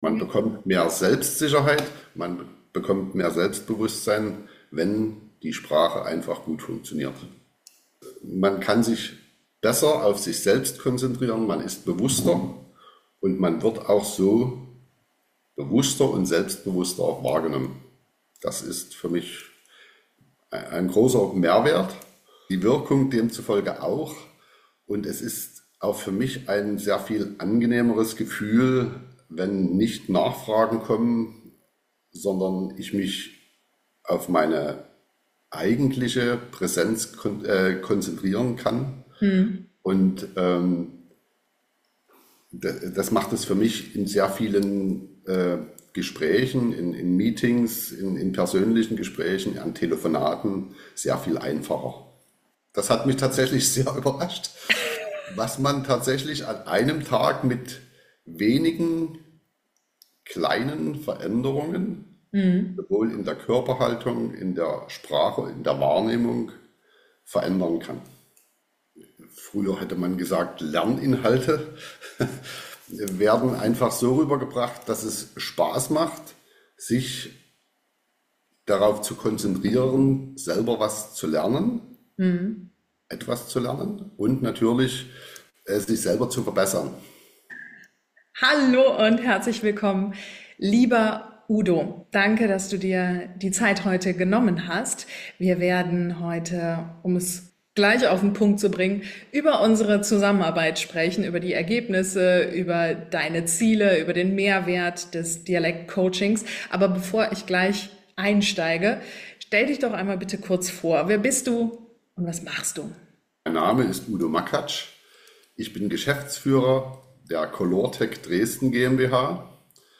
Ein ehrliches Gespräch über Wirkung, Sprache und Führung.